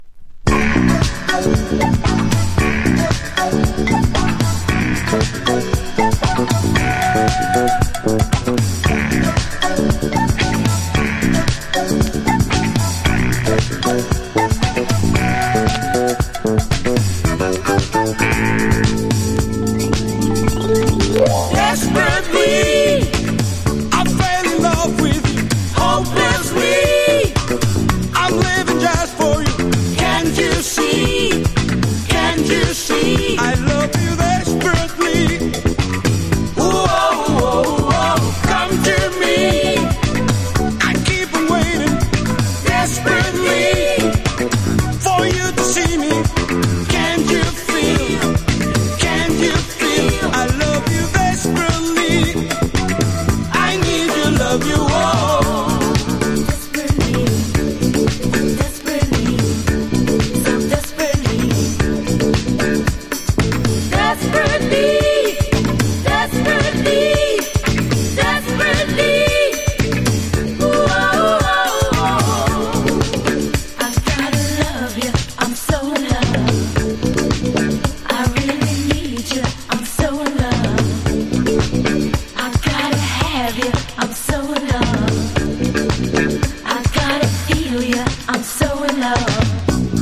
70’s ROCK
GARAGE# NEWWAVE DISCO